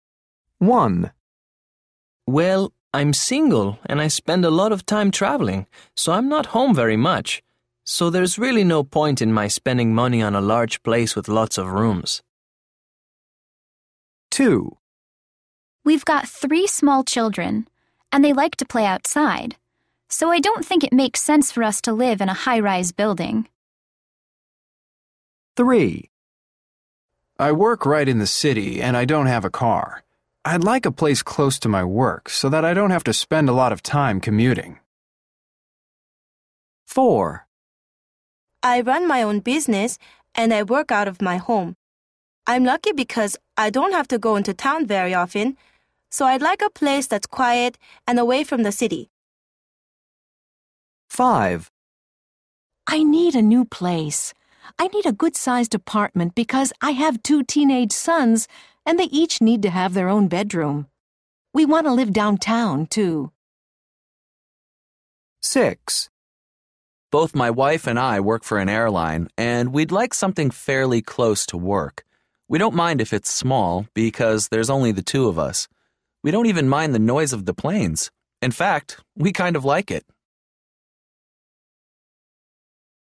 People are talking about housing. What kind of house or apartment does each person need?